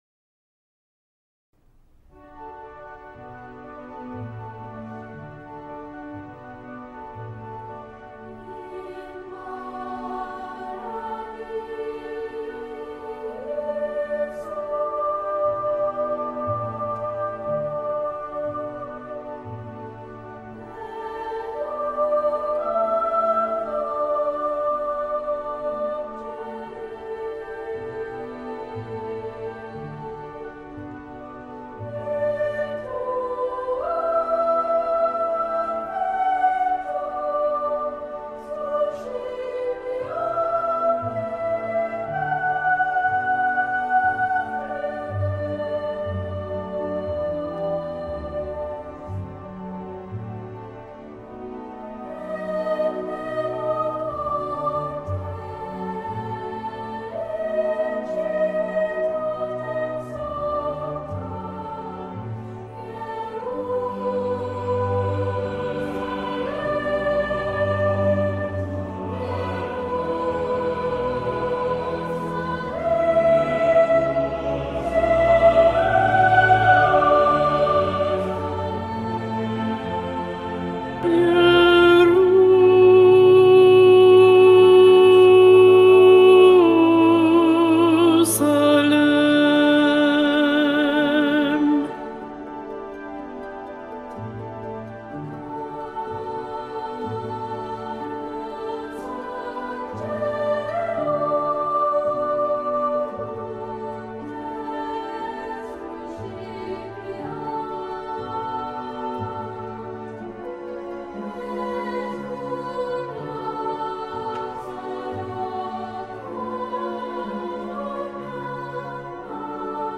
Contralto
Mp3 Profesora
CONTRALTO-In-Paradisum-PROFESORA-Mp3.mp3